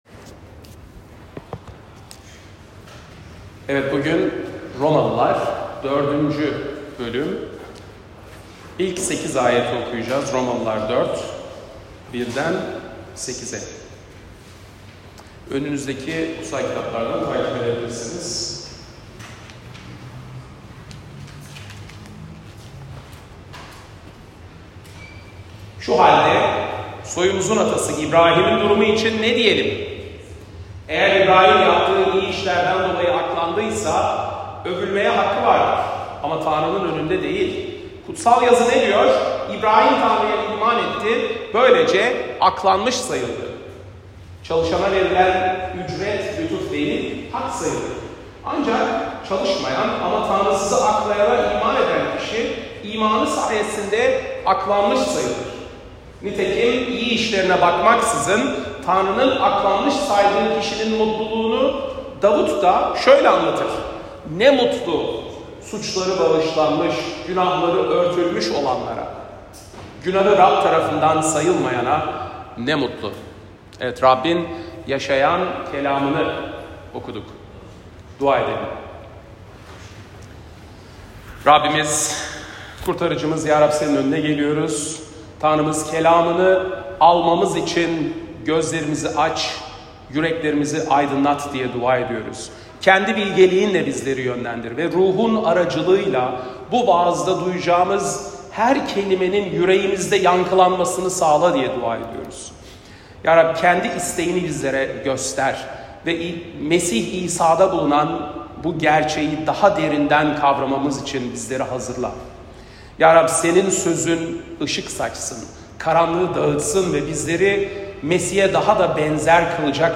Pazar, 2 Mart 2025 | Romalılar Vaaz Serisi 2024-26, Vaazlar